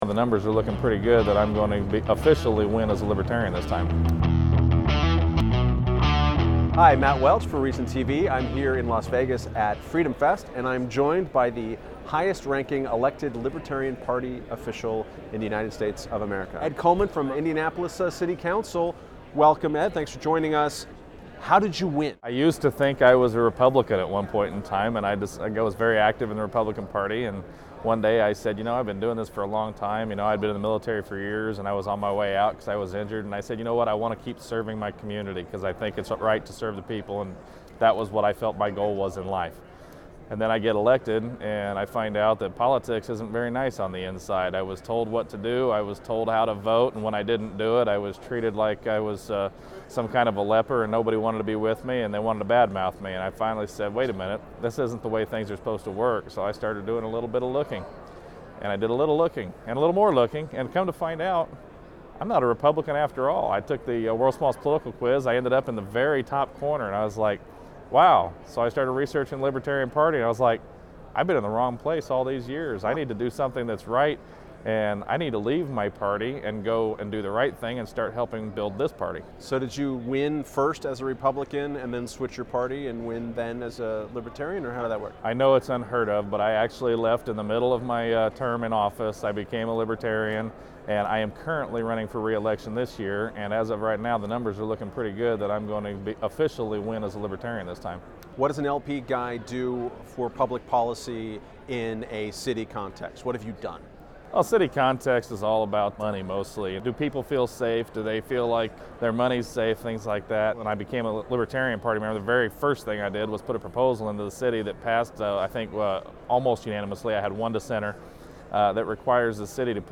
Libertarian To Be Elected? An Interview with Libertarian City Councilman Ed Coleman